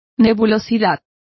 Complete with pronunciation of the translation of haziness.